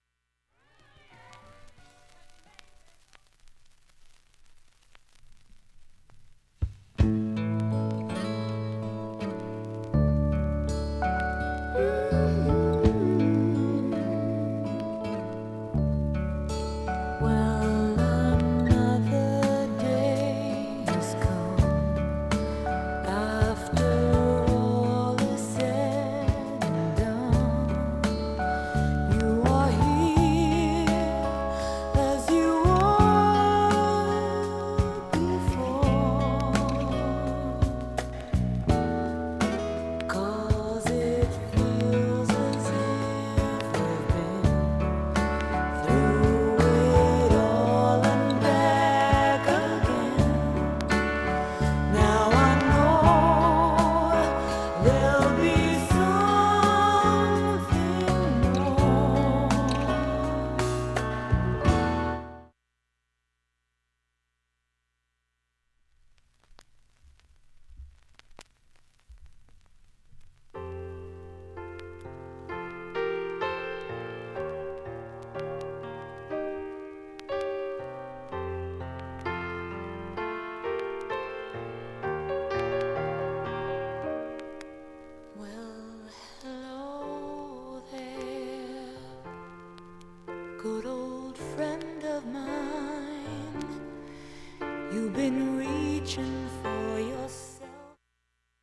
始めにかすかなプツが１２回出ます。 現物の試聴（上記録音時間１分３８秒）できます。